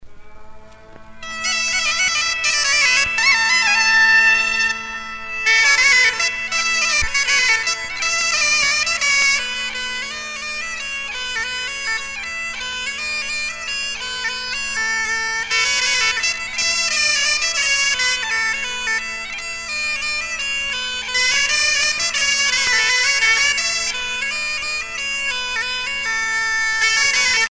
danse : gavotte bretonne